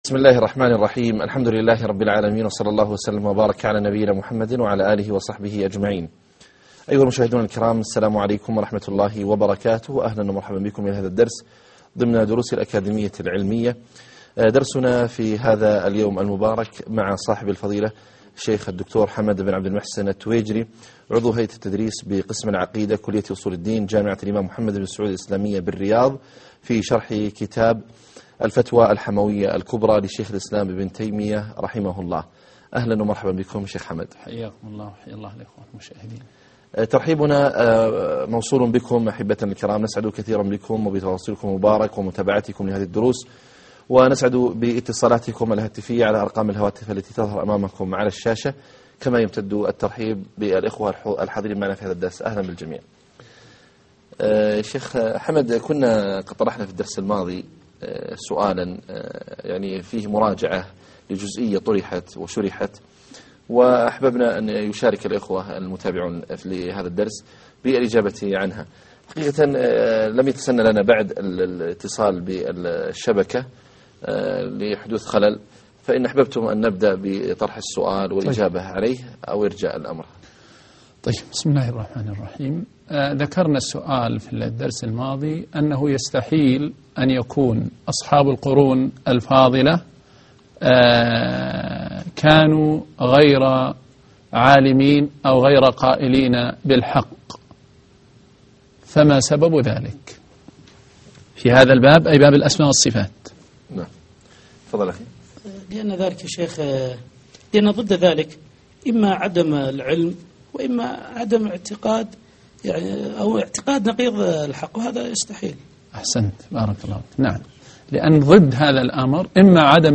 الدرس 4 طريقة السلف أسلم وأعلم وأحكم